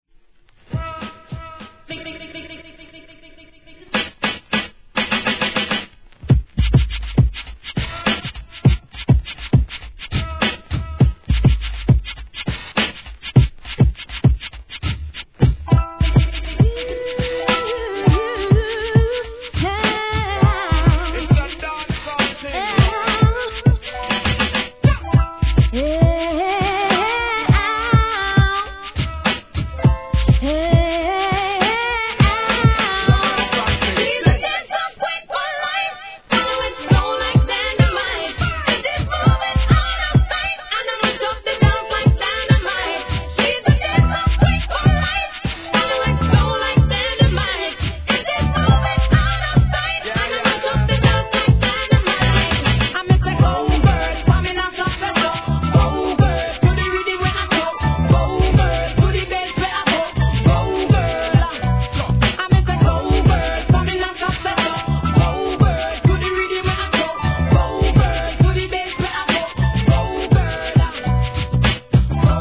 1. HIP HOP/R&B
人気のブレンド、REMIXシリーズ!!!